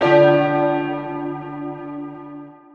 Key-bell_147.1.1.wav